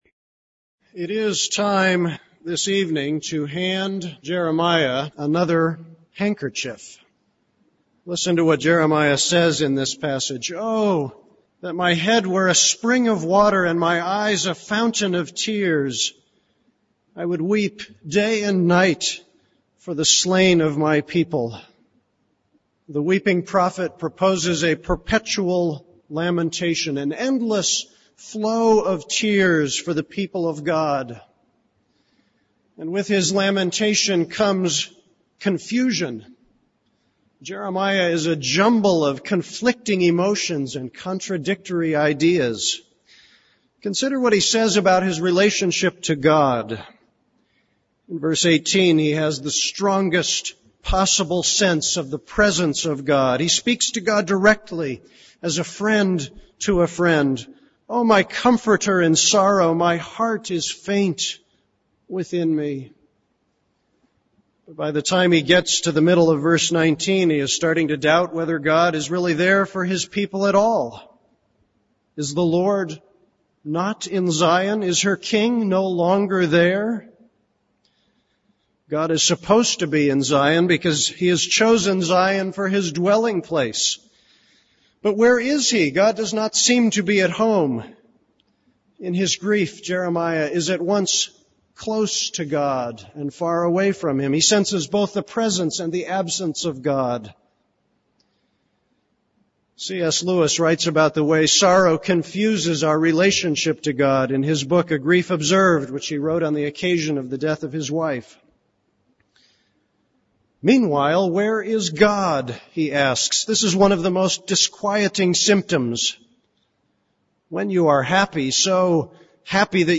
This is a sermon on Jeremiah 8:18-19.